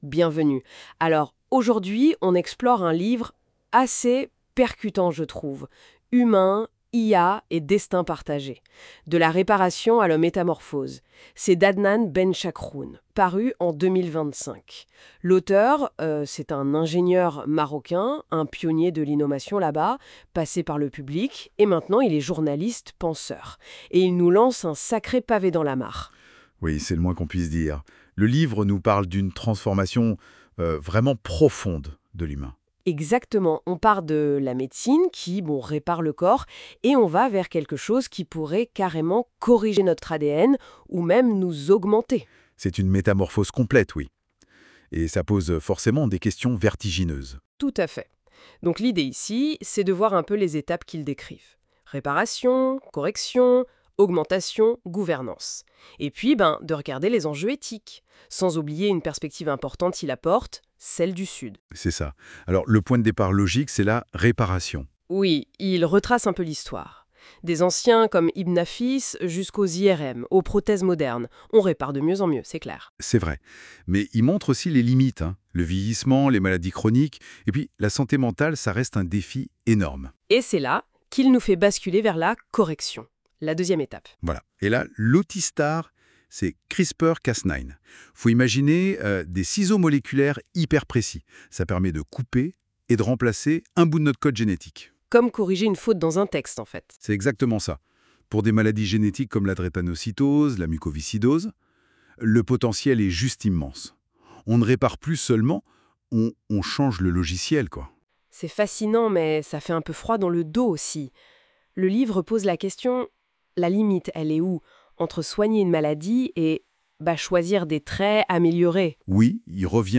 Débat sur le livre (14.85 Mo) Q1 : Qu'est-ce que le concept d' "humain augmenté" et comment se distingue-t-il de la médecine réparatrice traditionnelle ?